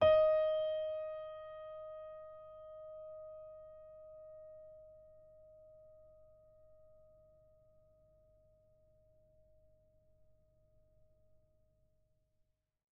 sampler example using salamander grand piano
Ds5.ogg